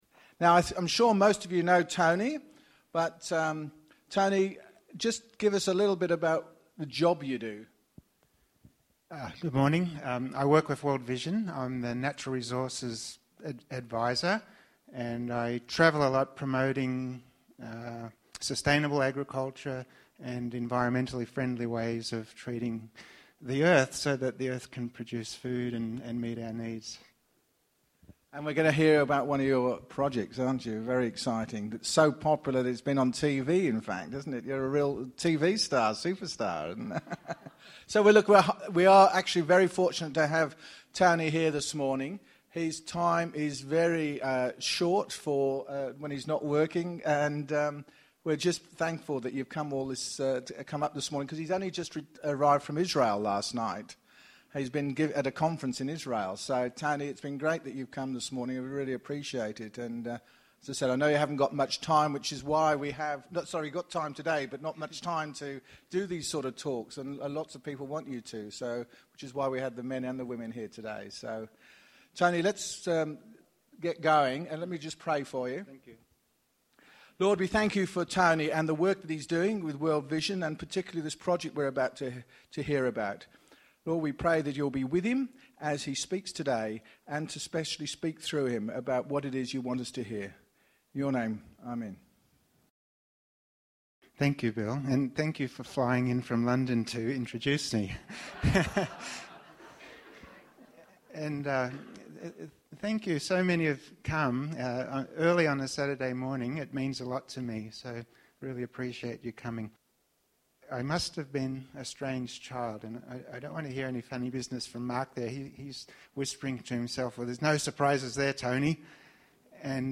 Tony talks about his journey in regreening Niger and beyond. In this sermon, Tony Rinaudo speaks on the theme of 'Journeys in Regreening' as part of the series 'Men's Breakfast'.